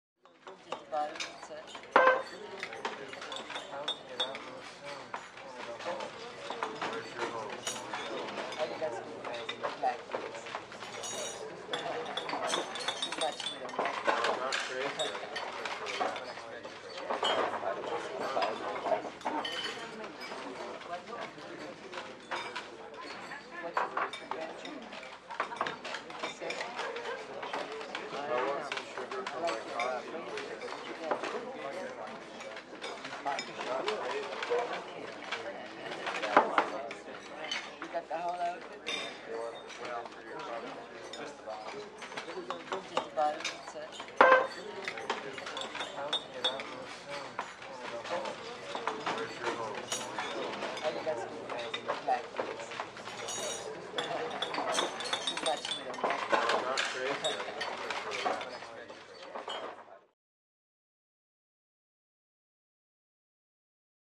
RestaurantAmbience TE038601
Restaurant Ambience, Very Subdued Feel, Distant Voices, Medium Close Up Glass & Silverware Movement.